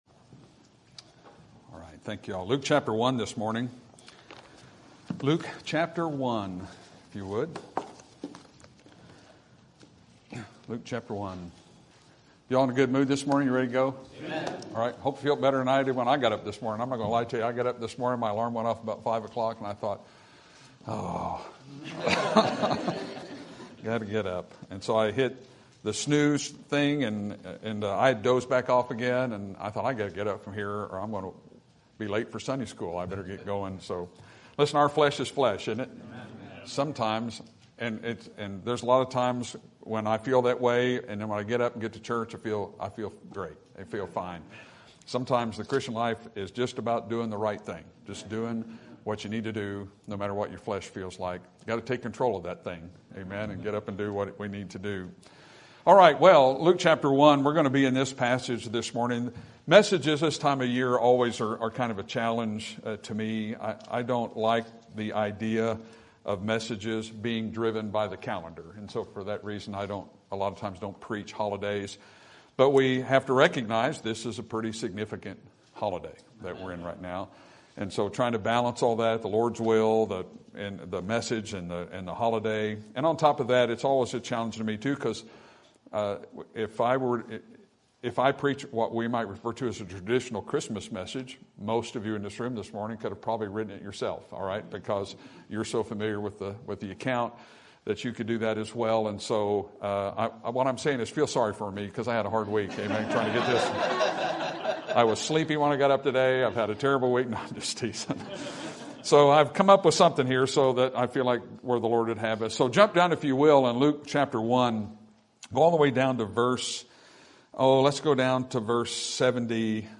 Sermon Topic: General Sermon Type: Service Sermon Audio: Sermon download: Download (20.04 MB) Sermon Tags: Luke Jesus John Day Spring